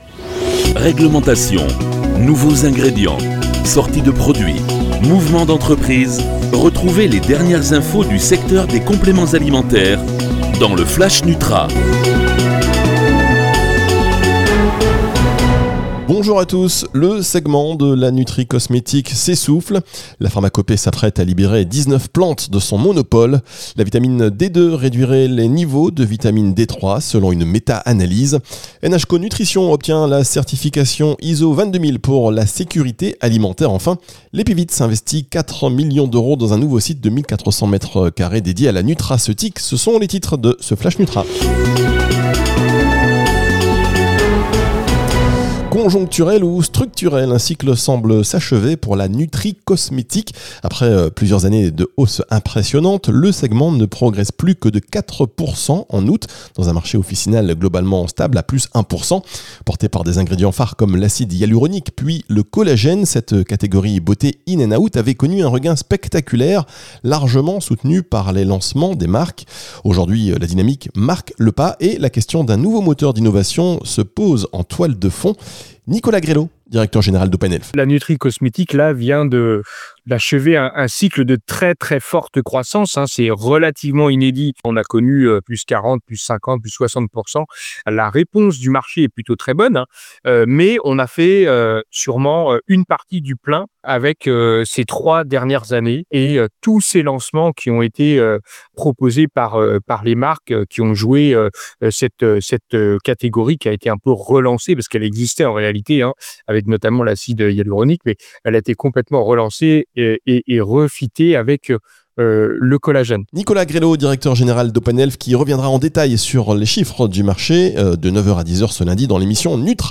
Les titres de votre flash Nutra: